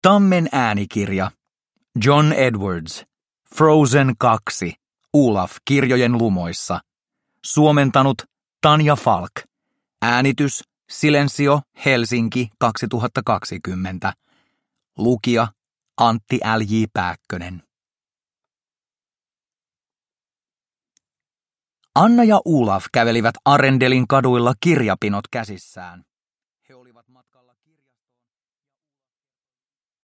Frozen 2 Olaf kirjojen lumoissa – Ljudbok – Laddas ner